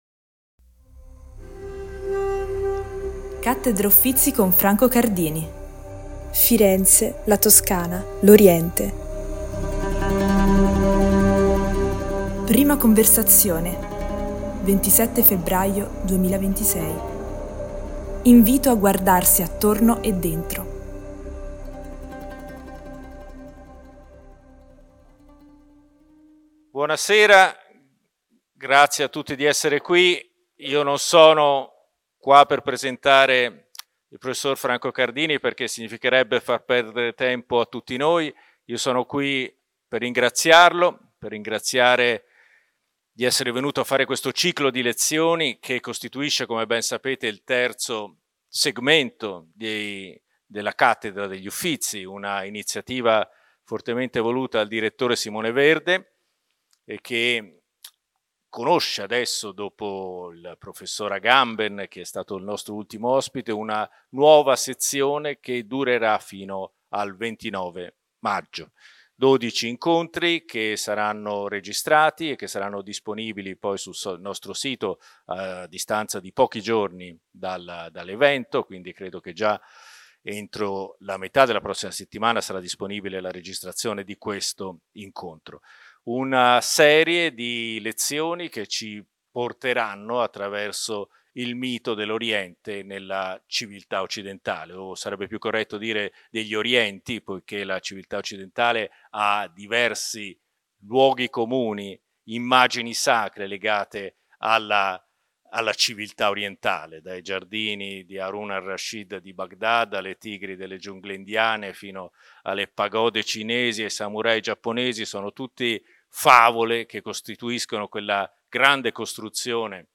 Lo storico Franco Cardini 'getta un ponte' tra Oriente e Occidente in 12 lezioni